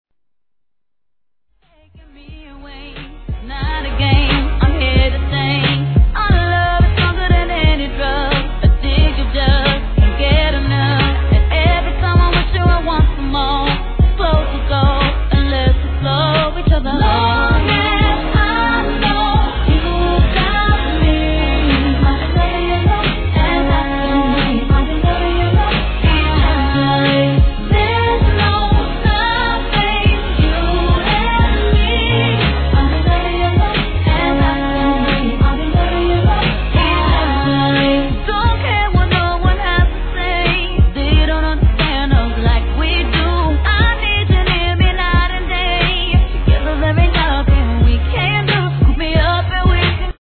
HIP HOP/R&B
(BPM90)